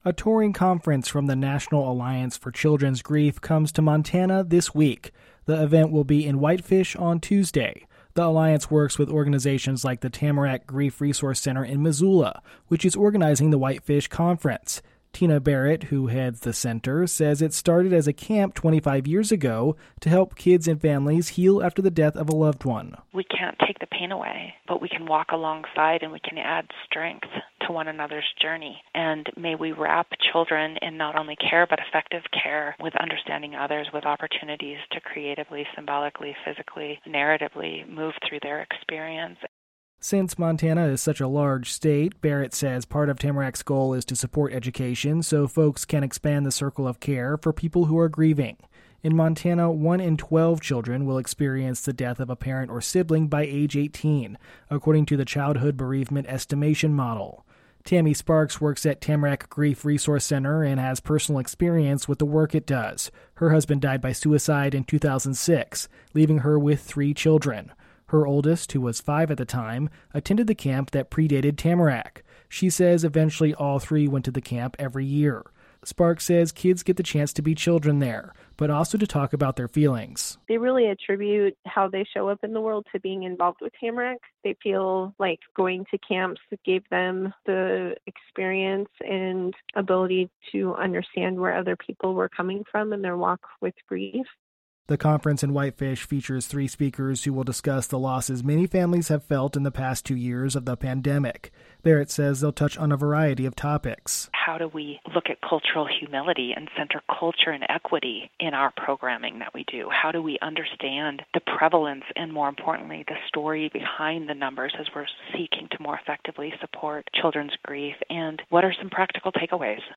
Participants at a conference coming to Whitefish this week will explore how to support children who are grieving. Comments from two representatives of the Tamarack Grief Resource Center